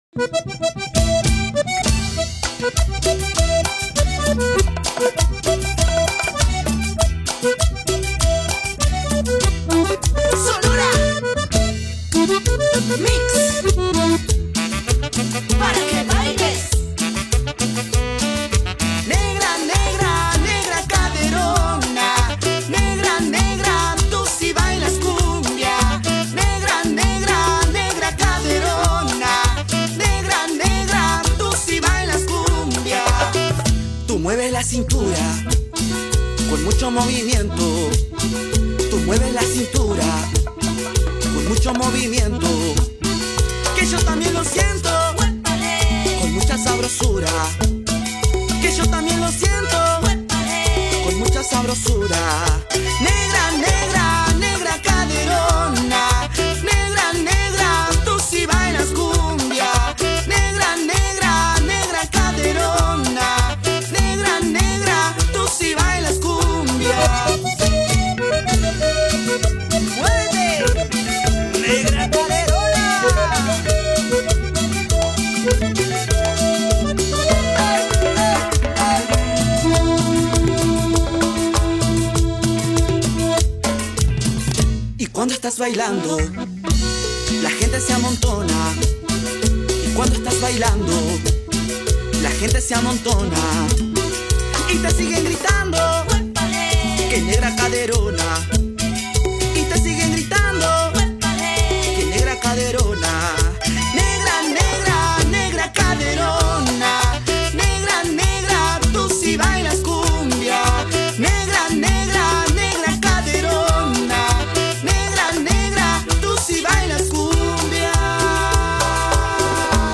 Banda salteña